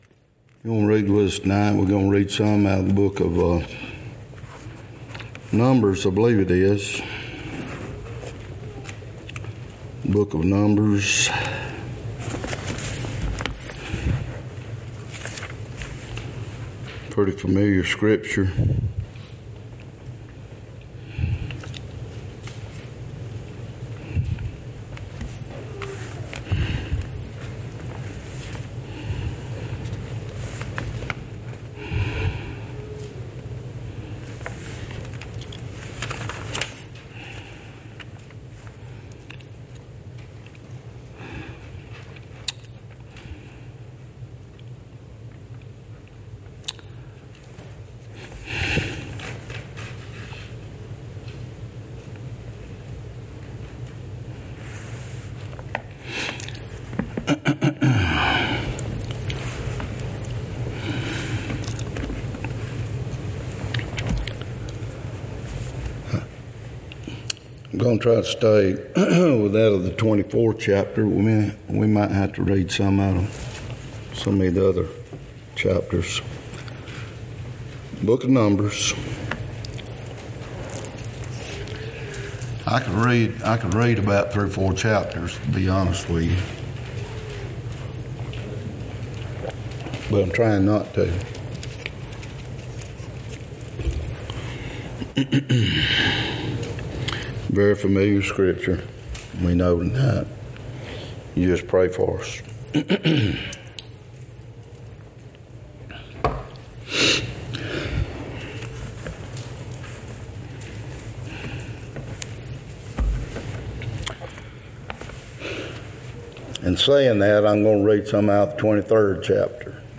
2024 Passage: Numbers 23:1-30, 24:1-14 Service Type: Wednesday night Topics